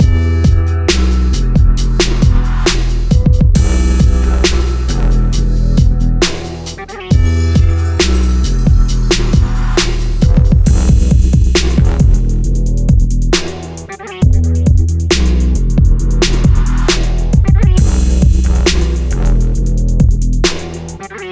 • fury brass trap sample - Em.wav
fury_brass_trap_sample_-_Em_VQl.wav